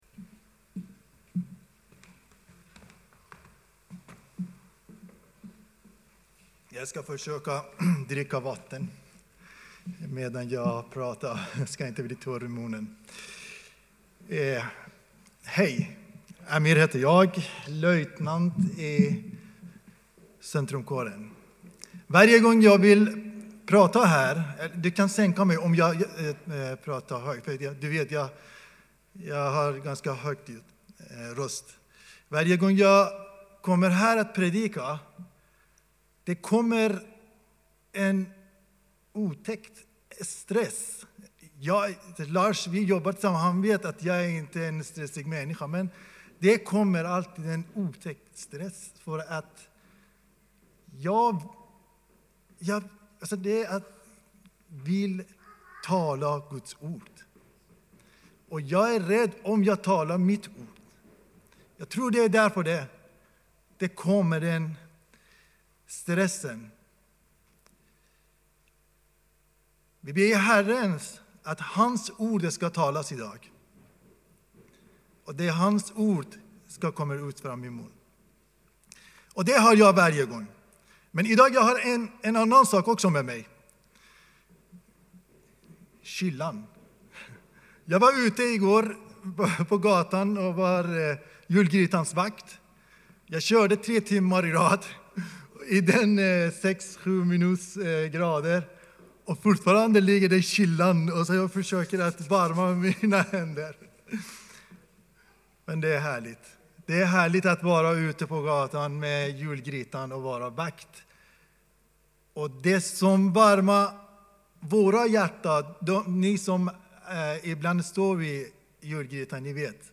predikan-11-dec-3-advent.mp3